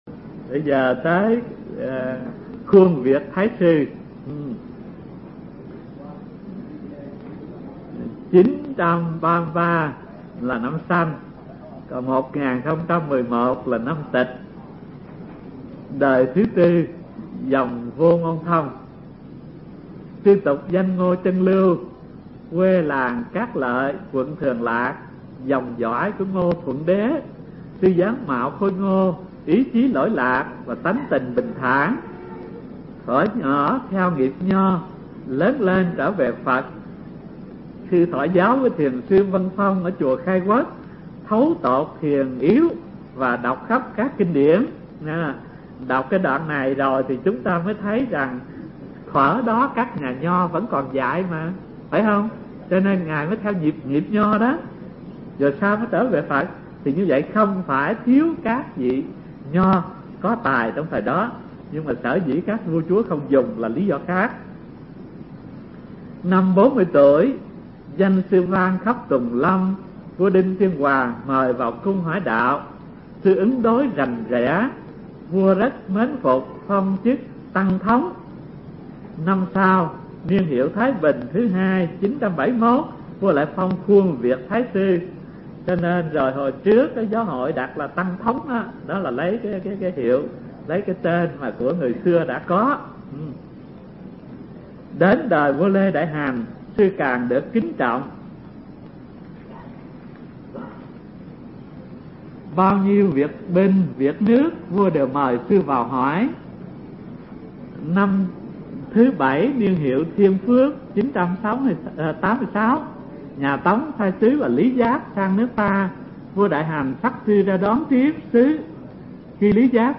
Tải mp3 Pháp Âm Thiền Học Phật Giáo Việt Nam 60 – Khuông Việt Thái Sư (Đời 4 Dòng Vô Ngôn Thông) – Hòa Thượng Thích Thanh Từ giảng tại trường Cao Cấp Phật Học Vạn Hạnh, từ năm 1989 đến năm 1991